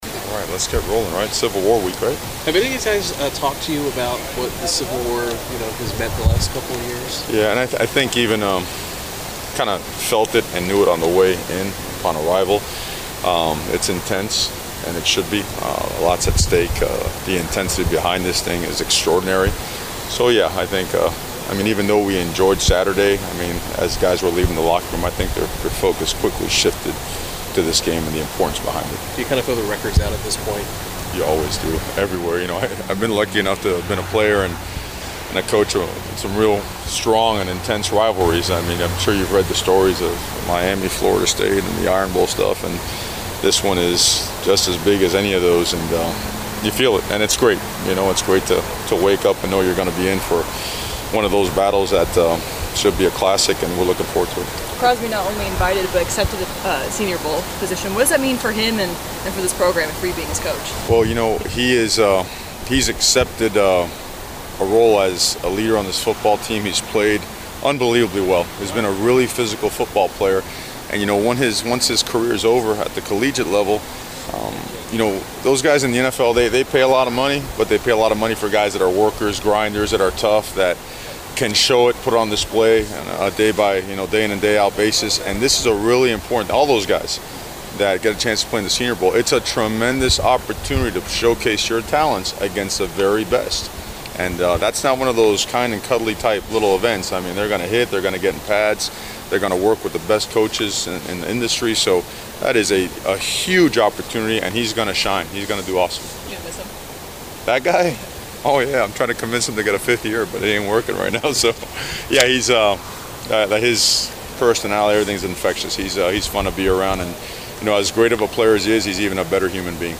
Mario Cristobal Media Session 11-21-17